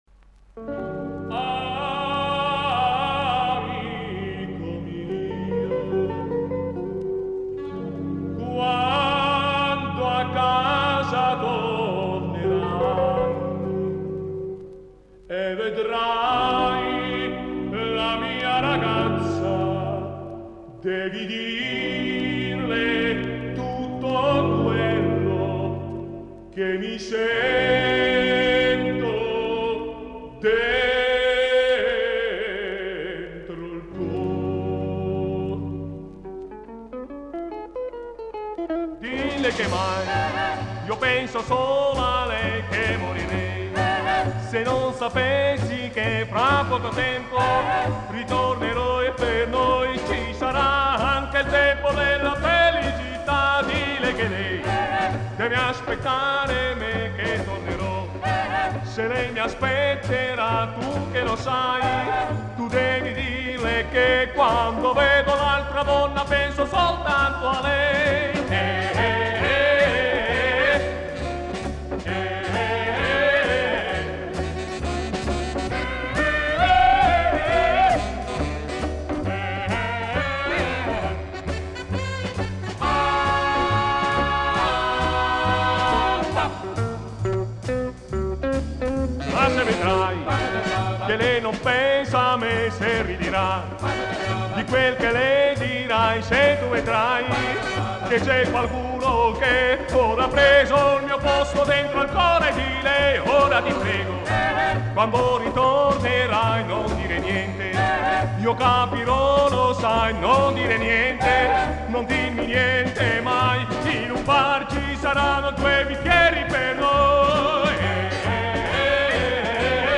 Запись стала какой то плоской.